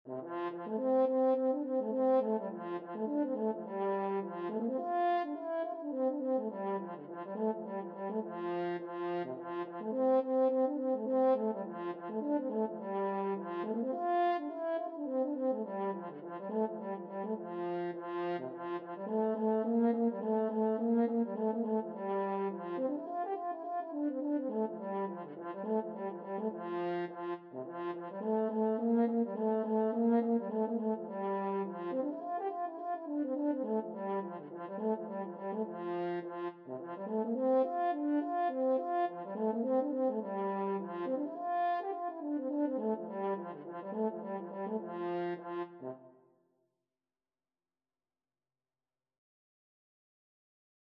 F major (Sounding Pitch) C major (French Horn in F) (View more F major Music for French Horn )
4/4 (View more 4/4 Music)
French Horn  (View more Intermediate French Horn Music)
Traditional (View more Traditional French Horn Music)